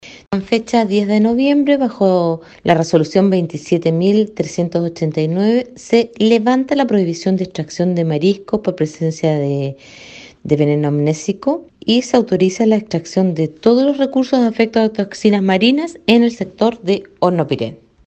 La seremi de Salud de Los Lagos informó de la resolución tras los muestreos llevados a efecto y procedió a abrir este punto de la provincia de Palena a las tareas propias de los recolectores, como lo indicó Marcela Cárdenas, seremi (S).